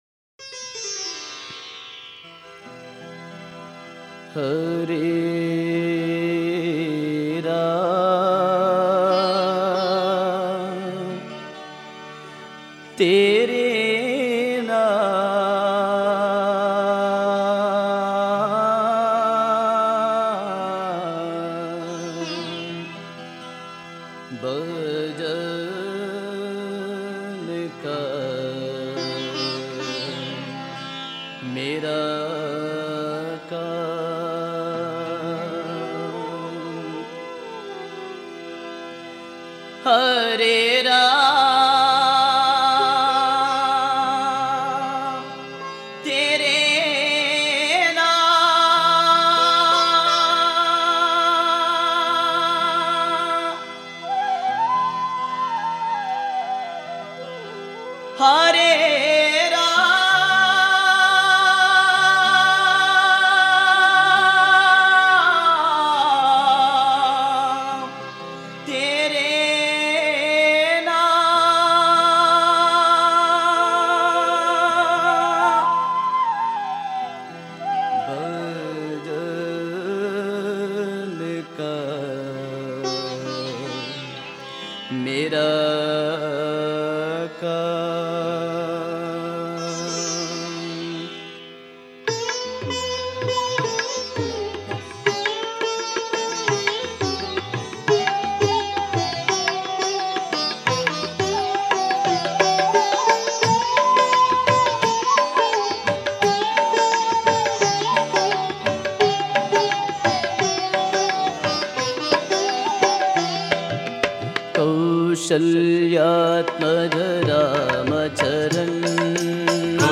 Home | Bhajan | Bhajans on various Deities | Rama Bhajans | 25-KAUSALYATMAJA-RAMACHARAN